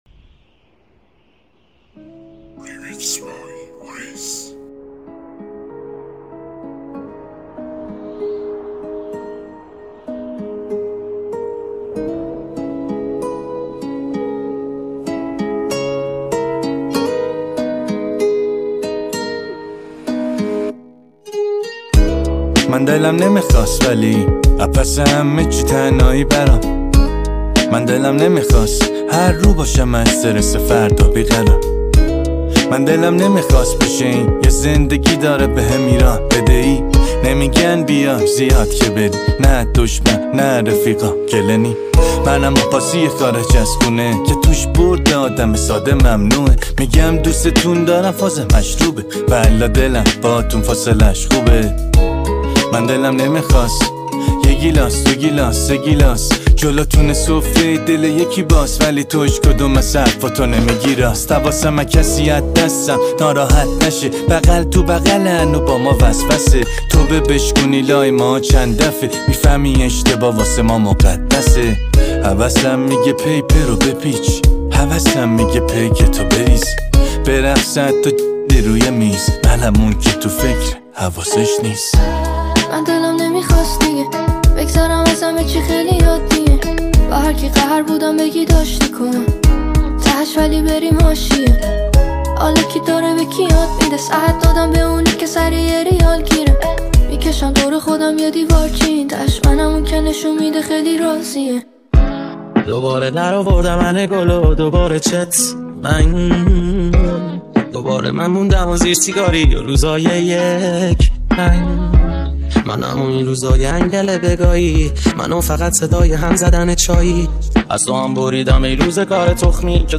ریمیکس غمگین